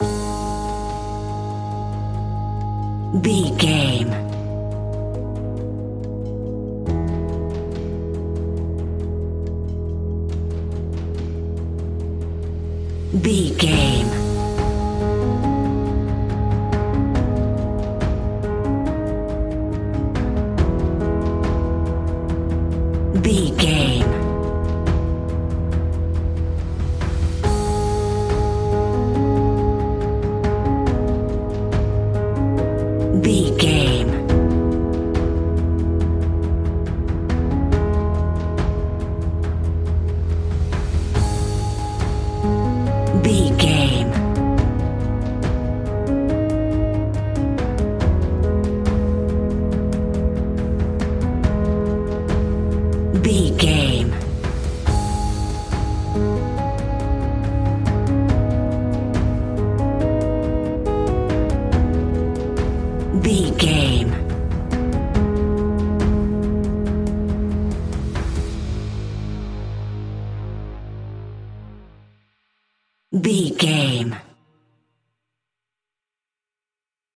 royalty free music
In-crescendo
Aeolian/Minor
G#
ominous
dark
haunting
eerie
synthesiser
drums
instrumentals
horror music